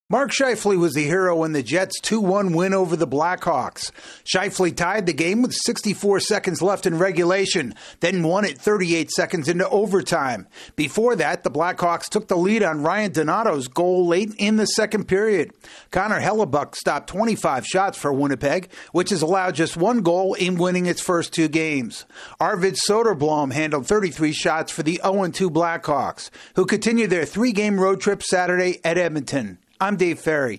The Jets score two late goals to beat the Blackhawks in OT. AP correspondent